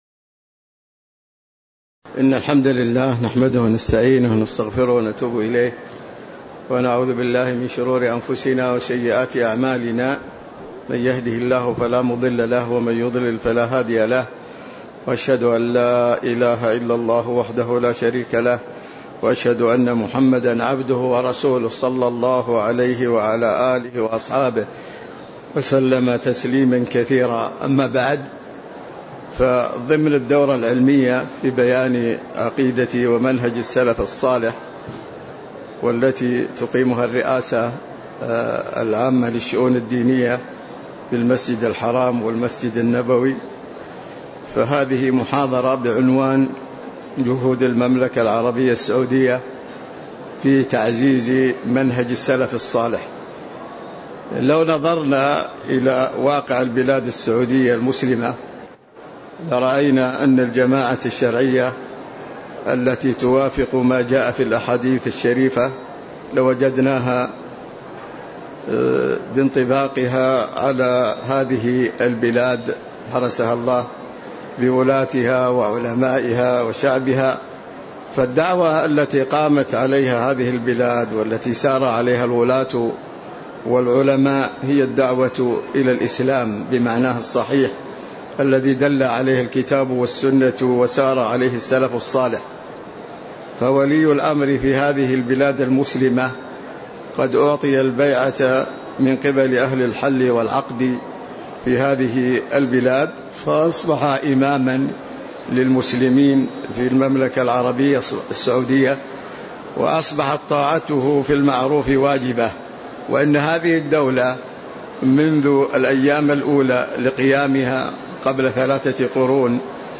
تاريخ النشر ٣ جمادى الآخرة ١٤٤٦ هـ المكان: المسجد النبوي الشيخ